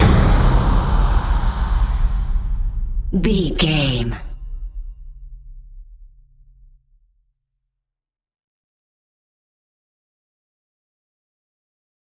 Hit With Whispers.
Sound Effects
Atonal
scary
ominous
eerie
drums
percussion
vocals
Horror Ambience